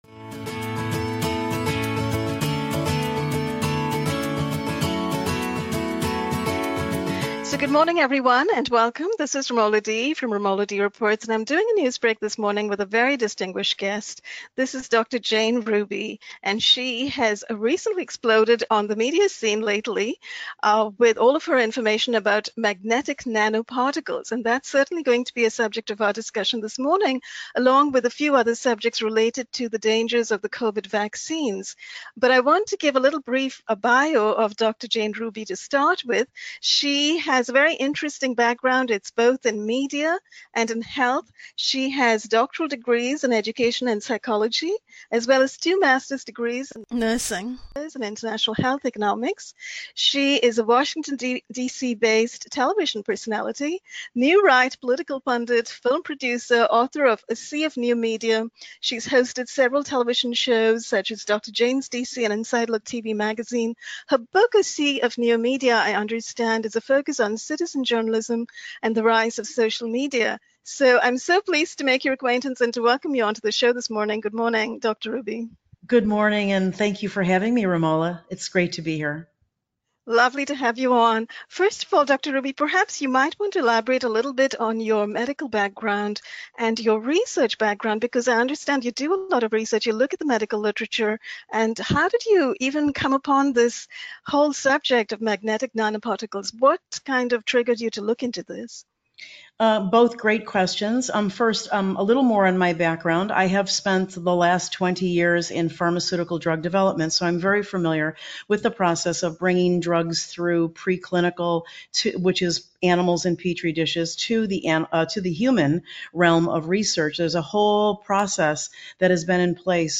Informative discussion